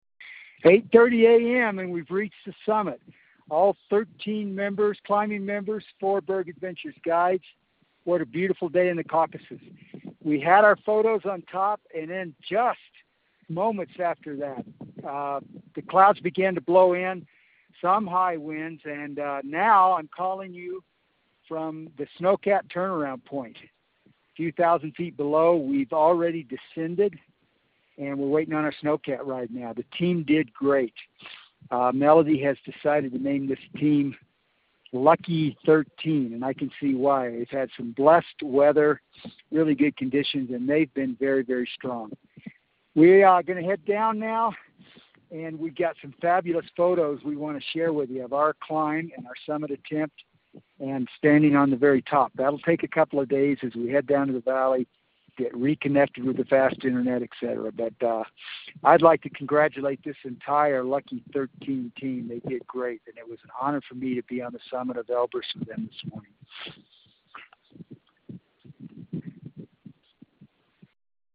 Call from the summit